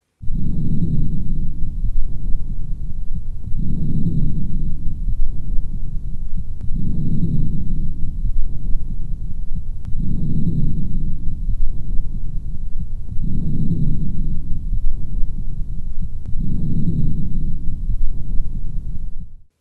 Хрипы влажные мелкопузырчатые